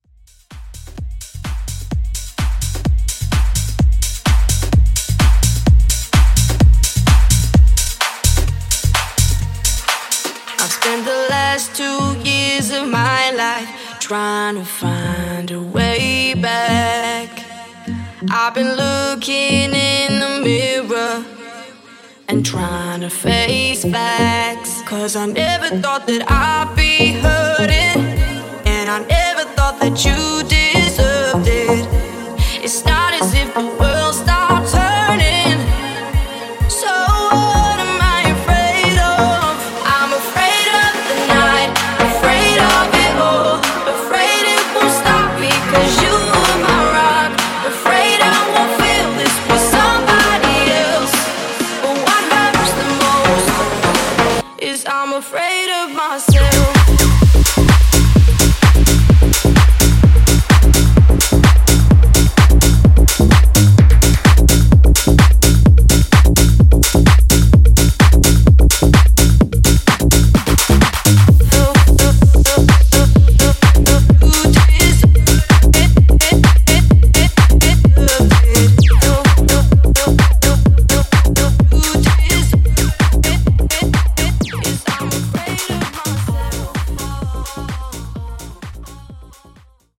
90s Redrum)Date Added